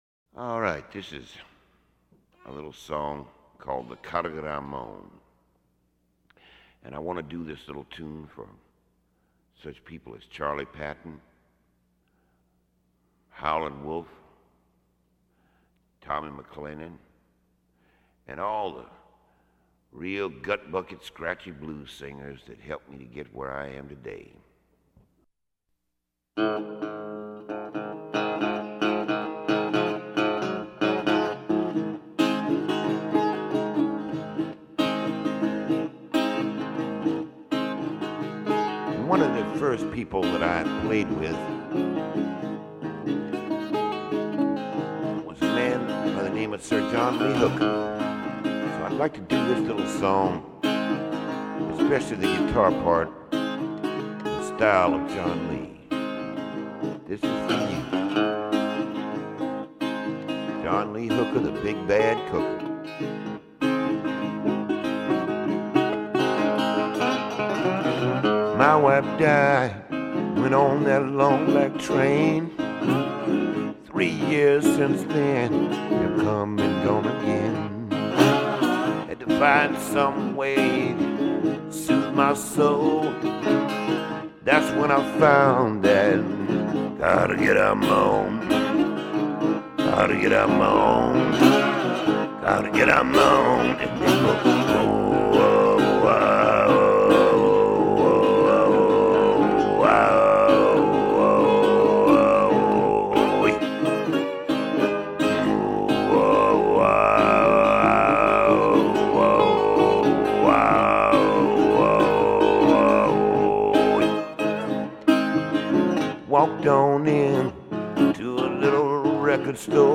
Tuvan throat singing